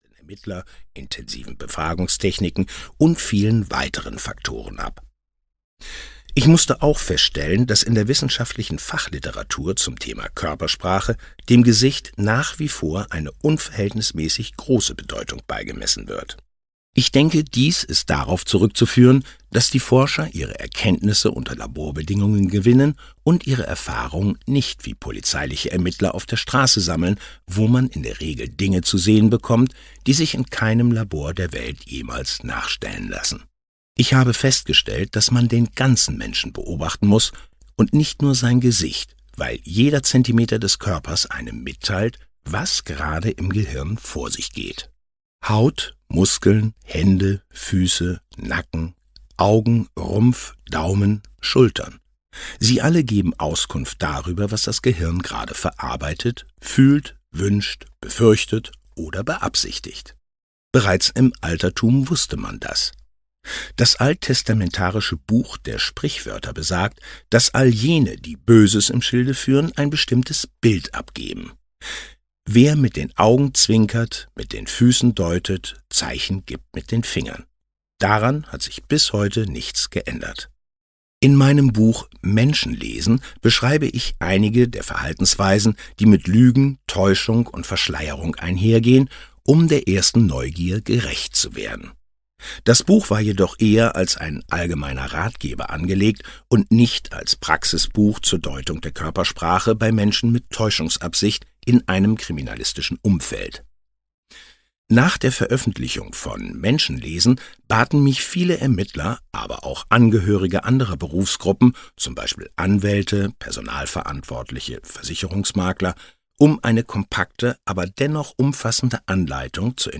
Der kleine Lügendetektor - Joe Navarro - Hörbuch